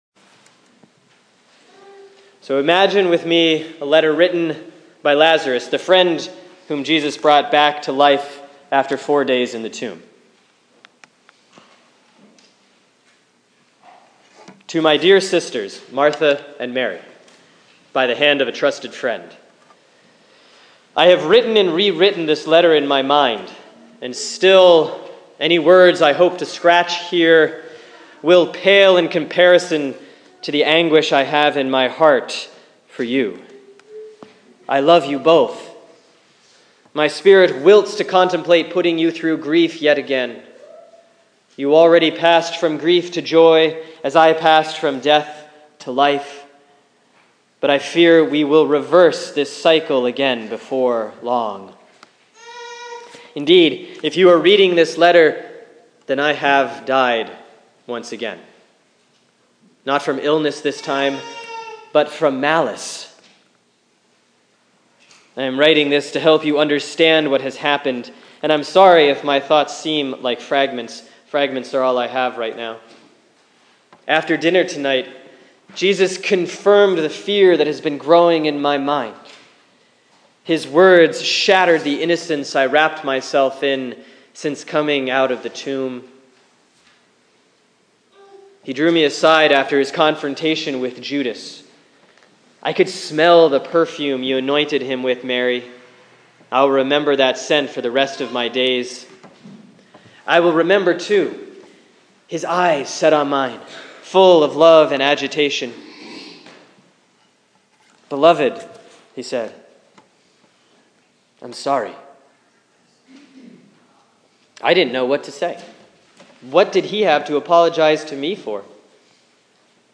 Sermon for Sunday, March 13, 2016 || Lent 5C ||  John 12:1-11